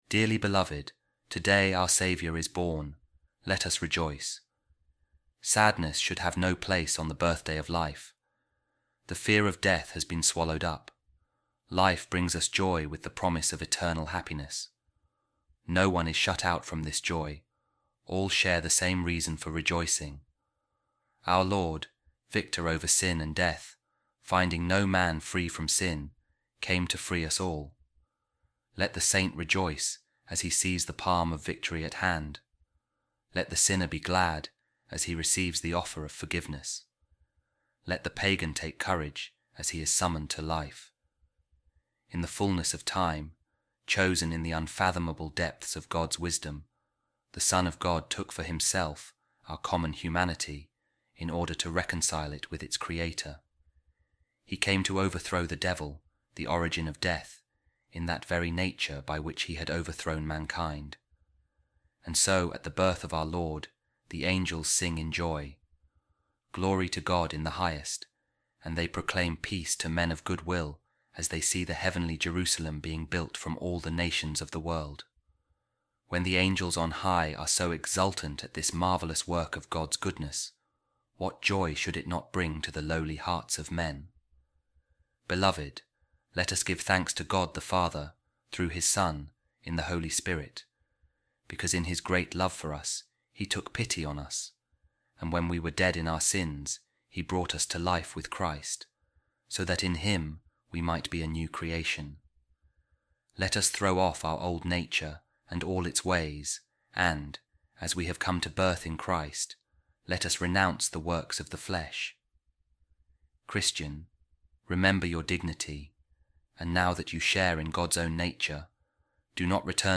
A Reading From A Sermon By Pope Saint Leo The Great | Oh Christian, Be Aware Of Your Nobility